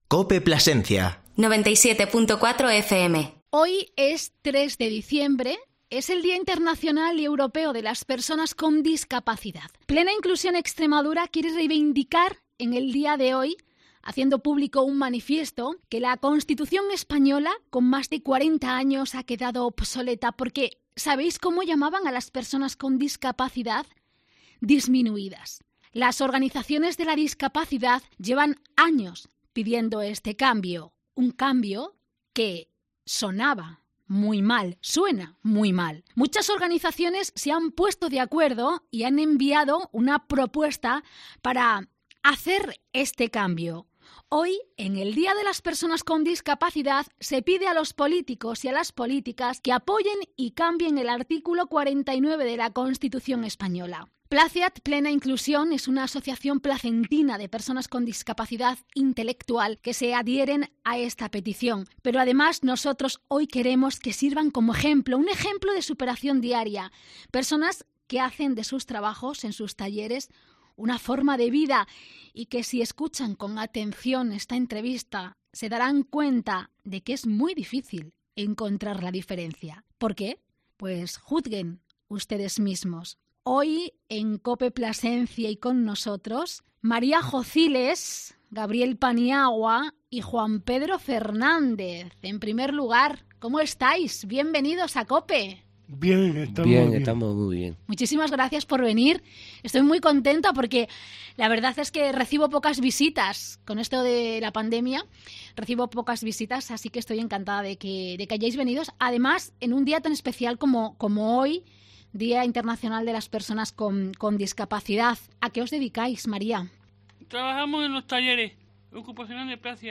Un gusto compartir micrófono con tres super capaces.